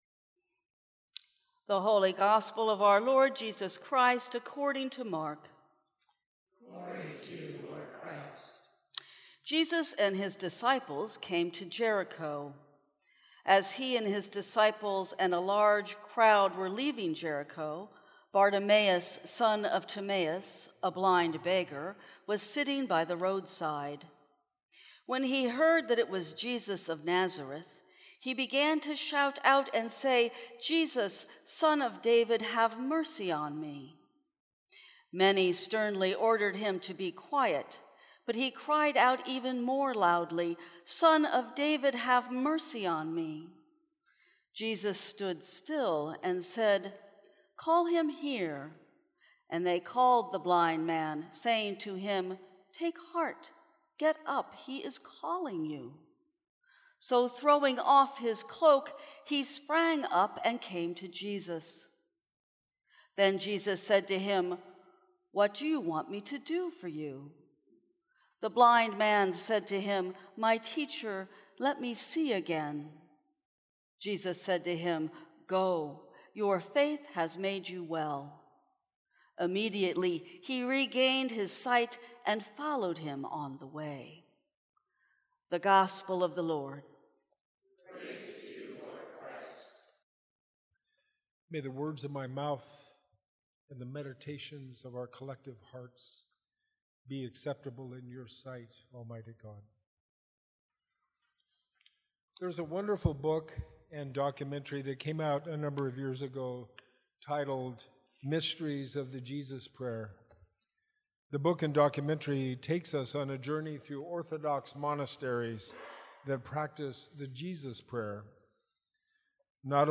Sermons from St. Cross Episcopal Church Mercy Oct 29 2018 | 00:11:20 1x 00:00 / 00:11:20 Subscribe Share Apple Podcasts Spotify Overcast RSS Feed Share Link Embed